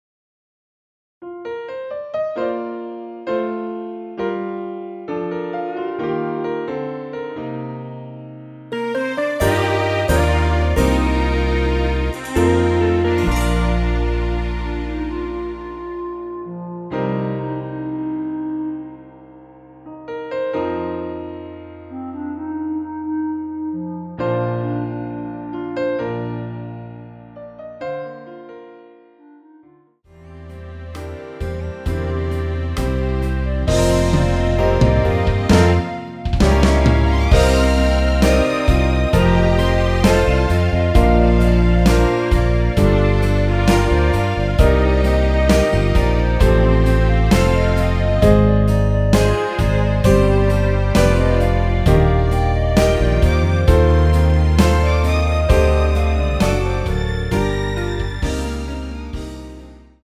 원키에서(-6)내린 멜로디 포함된 MR입니다.(미리듣기 확인)
Bb
앞부분30초, 뒷부분30초씩 편집해서 올려 드리고 있습니다.
중간에 음이 끈어지고 다시 나오는 이유는